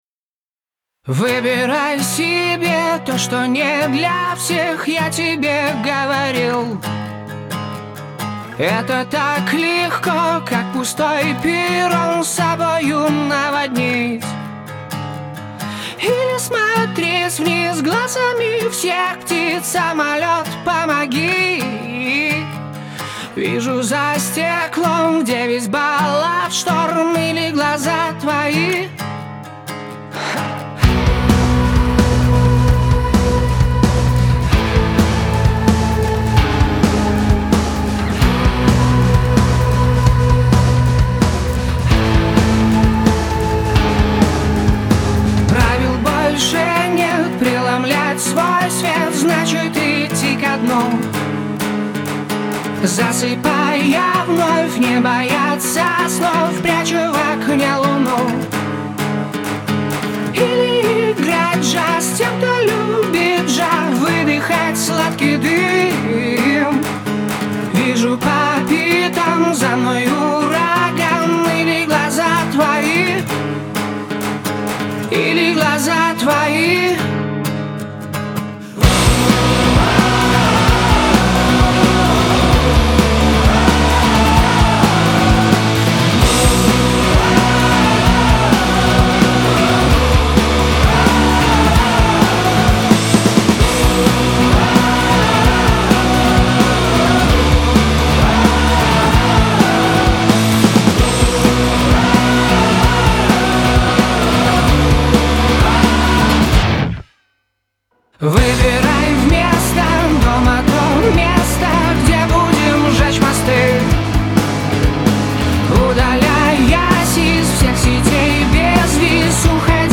рок-группы